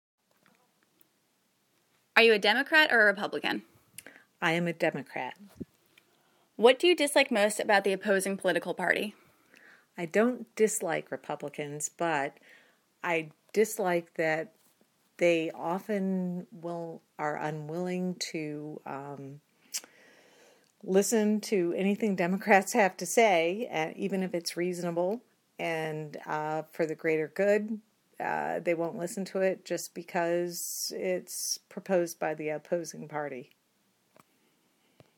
Interview- Party Lines